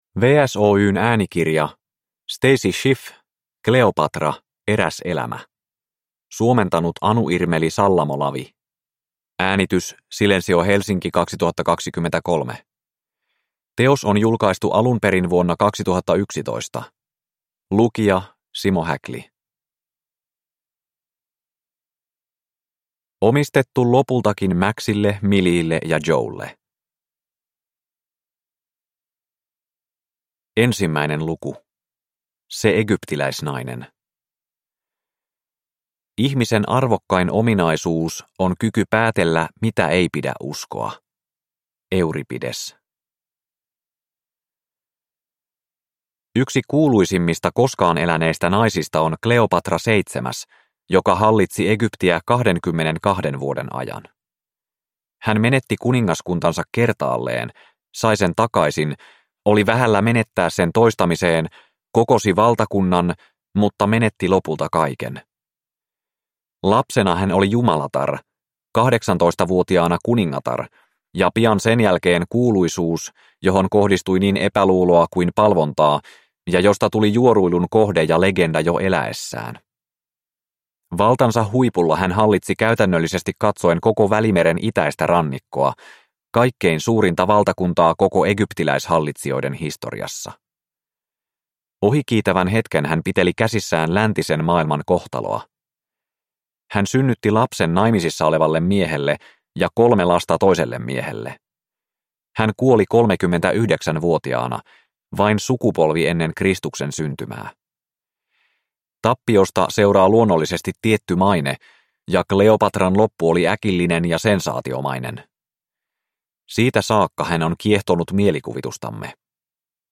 Kleopatra – Ljudbok – Laddas ner